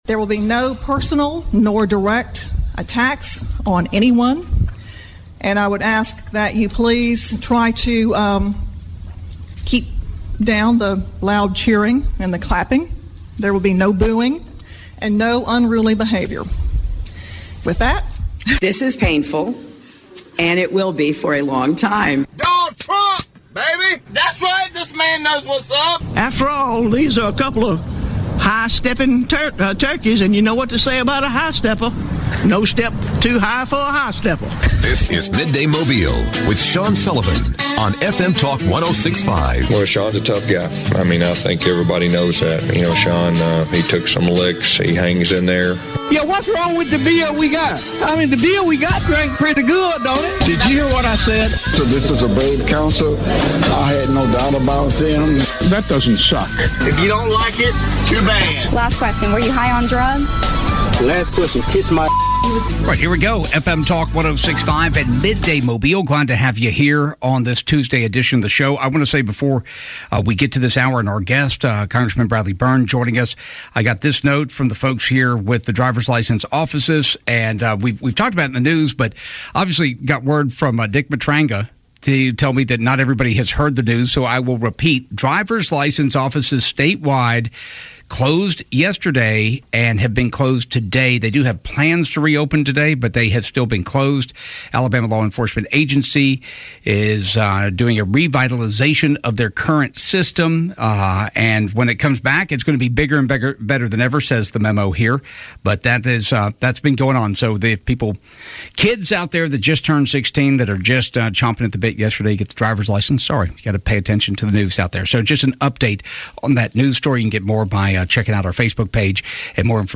Midday Mobile - Former Congressman Bradley Byrne sits down for a wide-ranging conversation including his thoughts on Russia and the Republican primary elections - April 19 2022